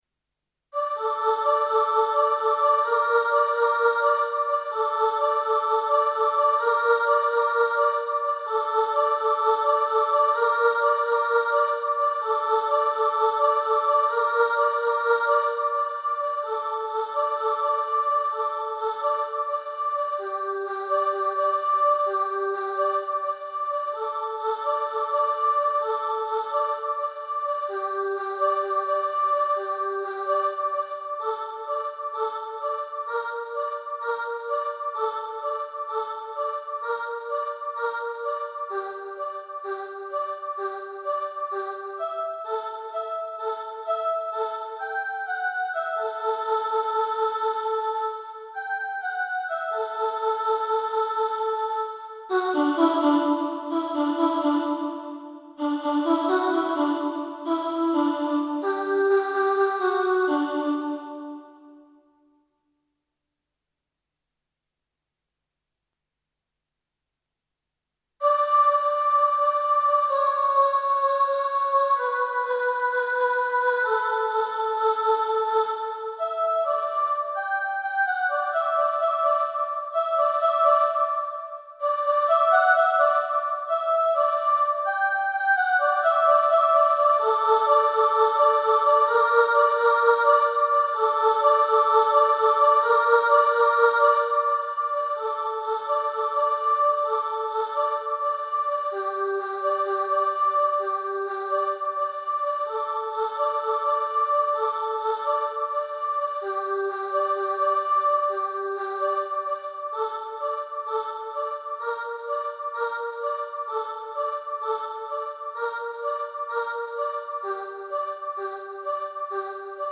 Mamma Mia (Soprano 1) | Ipswich Hospital Community Choir